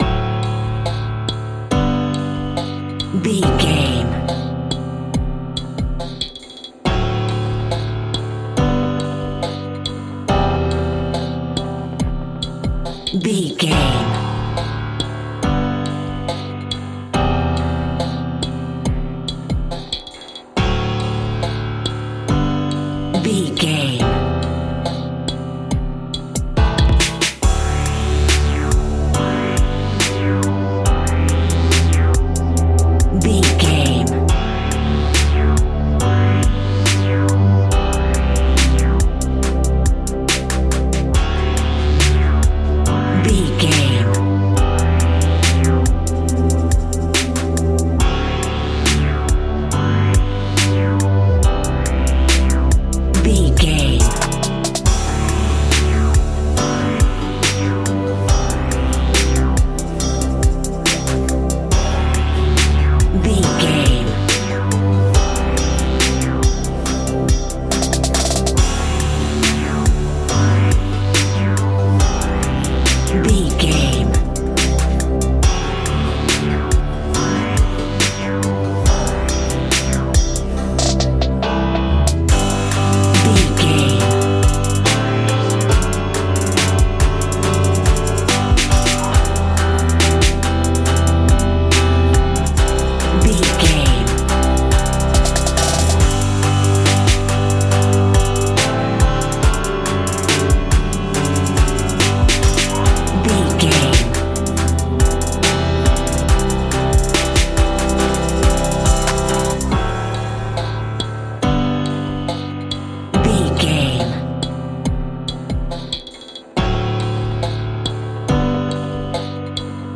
Chilled Electronica Music.
Ionian/Major
piano
drum machine
synthesiser
electric piano
chillwave